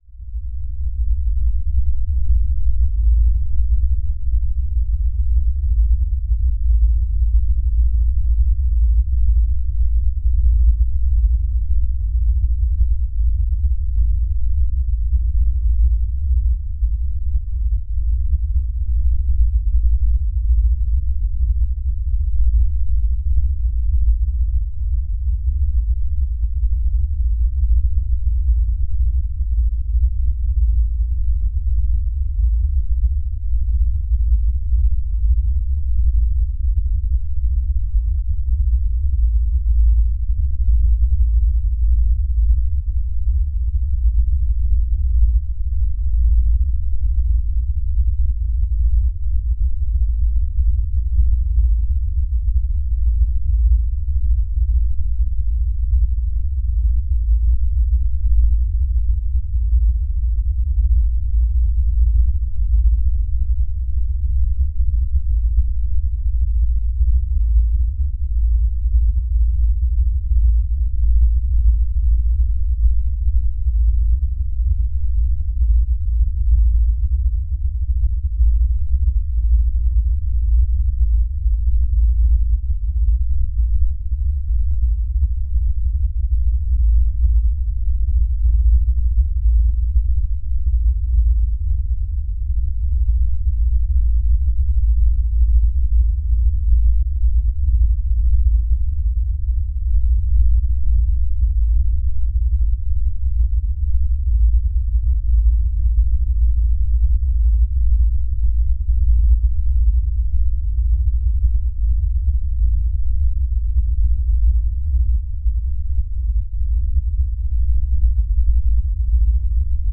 Звуки низкой частоты
Глубокие басы, инфразвук и резонансные волны могут вызывать неоднозначные ощущения — от легкого дискомфорта до сильной усталости.
Звук устрашающих низких частот